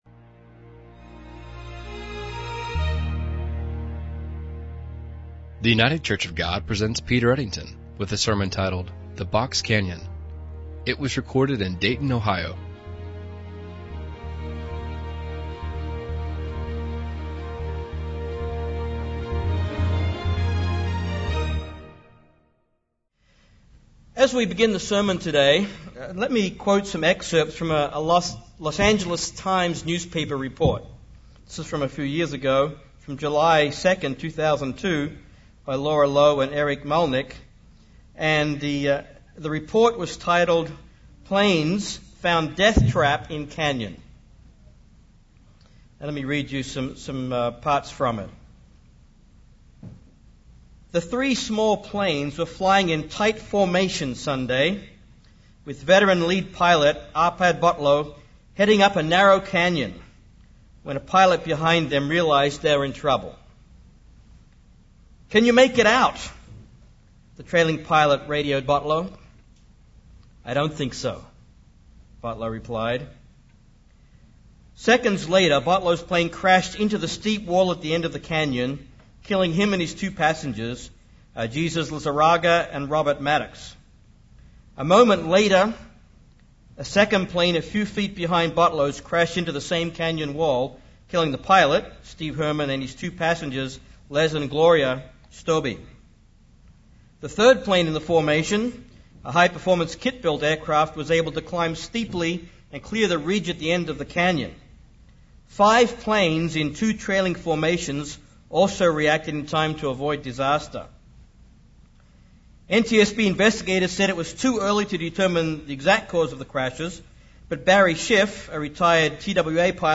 In this sermon, we are going to look at the Feast of Trumpets from a different angle. Lets go through five questions as we look at the wonderful and inspiring meaning of Trumpets.